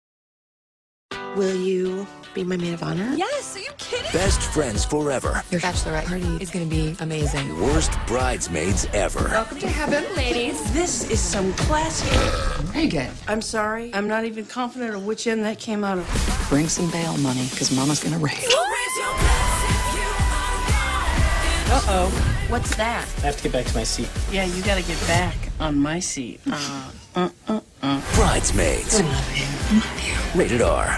TV Spots